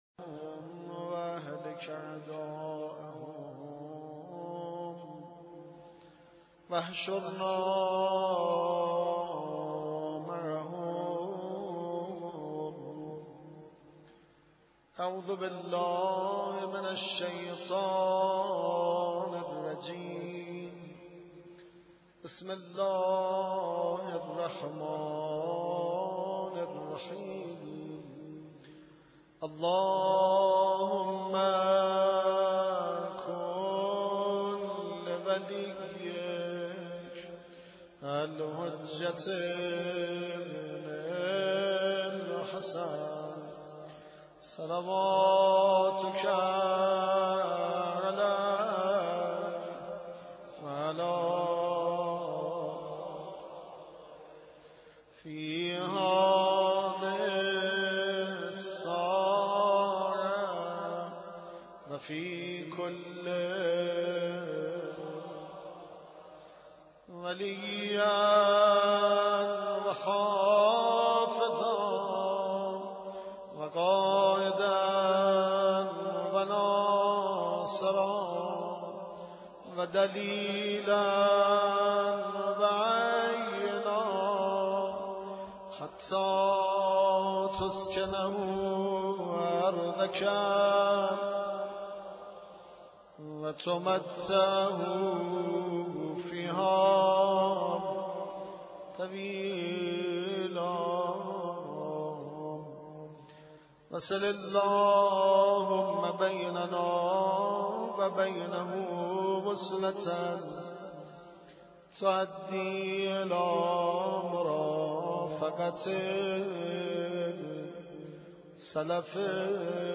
2 0 قرائت دعای کمیل